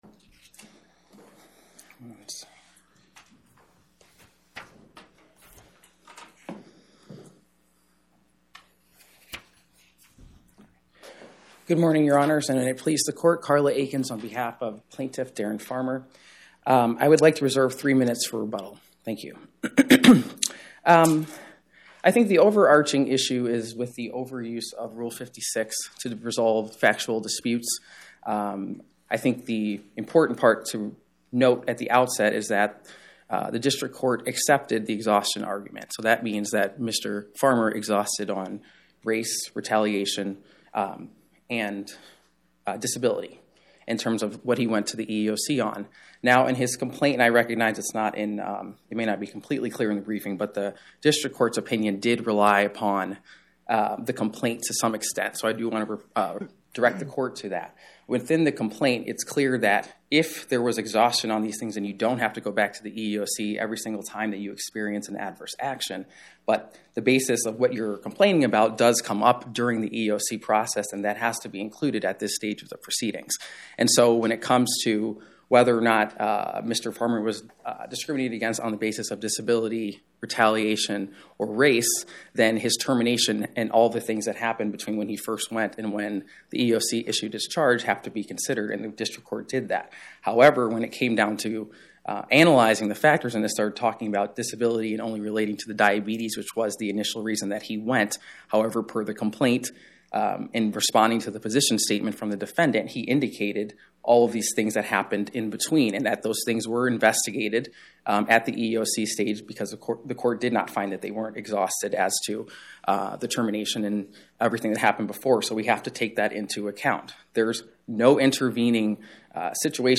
Oral argument argued before the Eighth Circuit U.S. Court of Appeals on or about 10/22/2025